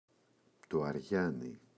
αριάνι, το [a’rʝani]